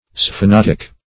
sphenotic - definition of sphenotic - synonyms, pronunciation, spelling from Free Dictionary
Sphenotic \Sphe*not"ic\, a. [Spheno- + ???, ???, the ear.]